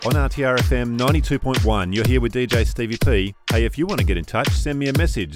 Male
English (Australian)
Deep, comforting, trustworthy, steady voice.
Live Announcer
Radio Presenter Demo
Words that describe my voice are Trustworthy, Calm, Deep.
1228PortfolioDemos_-_RadioDJ-short.mp3